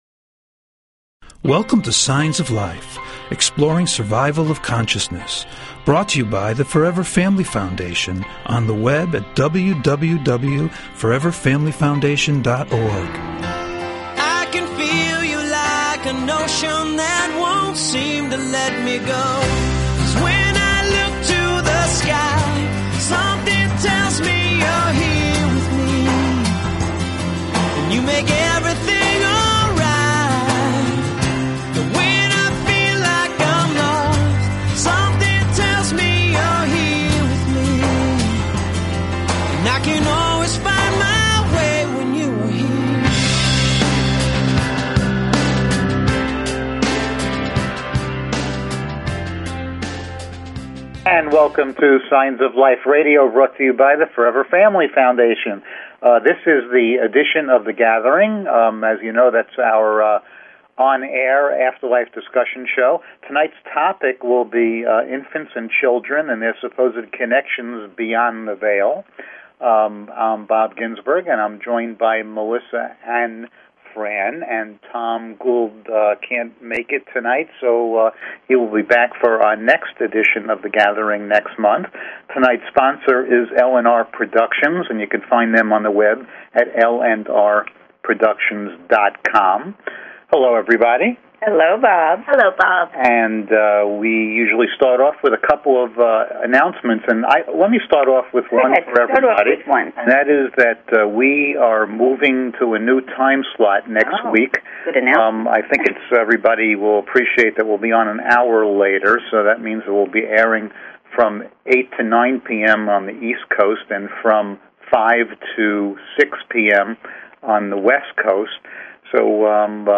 SHORT DESCRIPTION - discussion show - Topic: Children and their connections to the spirit world
Call In or just listen to top Scientists, Mediums, and Researchers discuss their personal work in the field and answer your most perplexing questions.